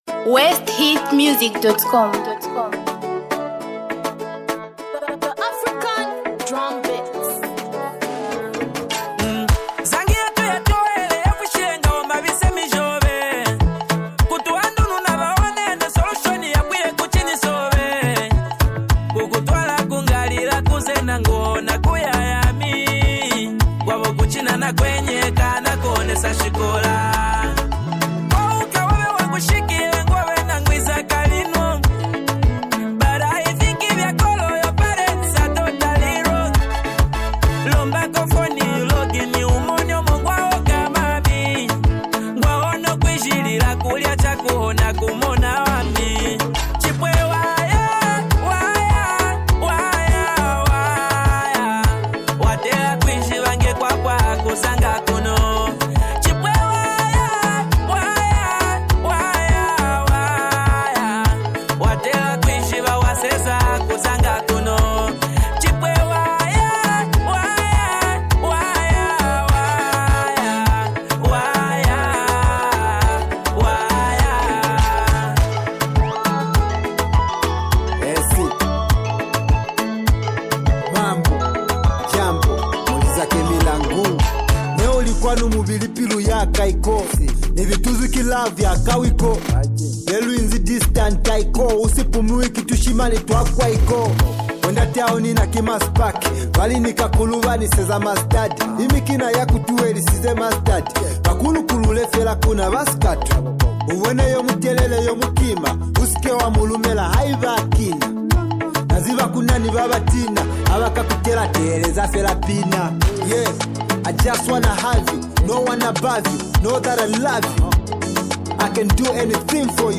North-Western Music